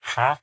haggle2.ogg